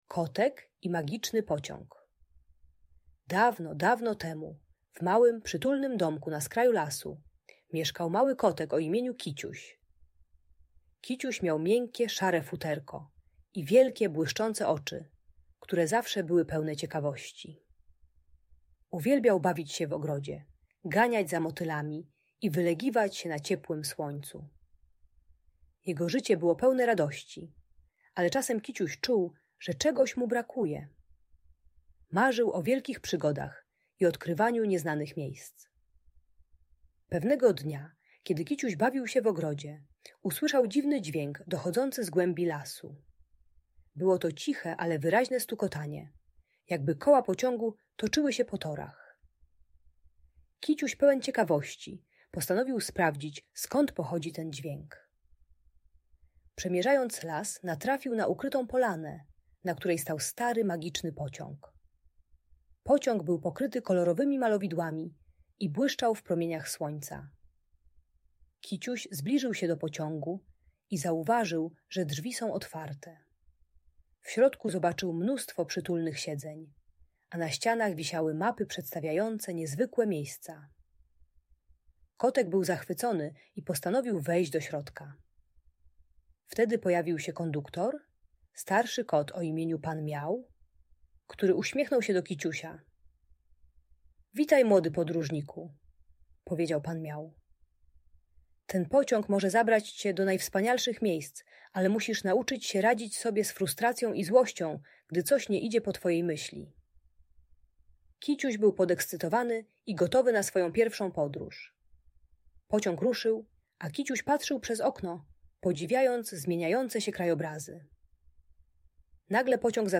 Kotek i Magiczny Pociąg - Bunt i wybuchy złości | Audiobajka